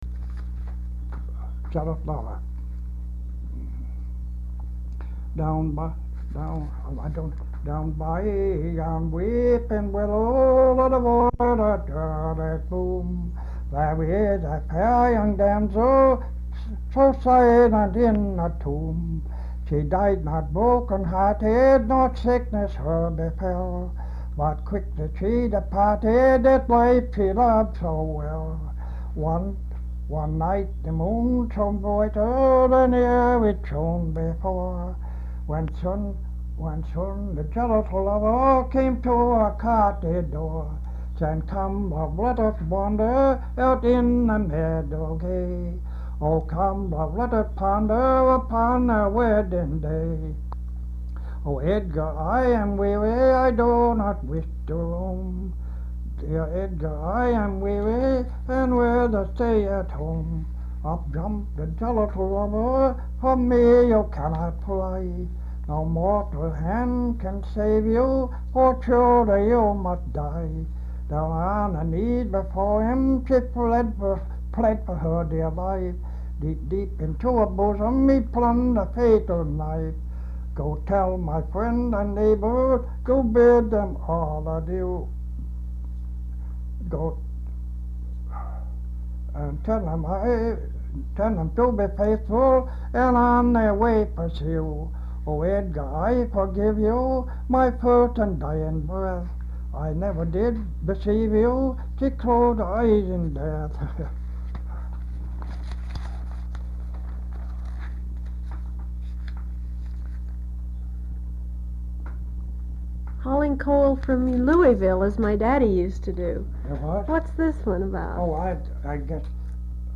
Folk songs, English--Vermont
sound tape reel (analog)
Location Marlboro, Vermont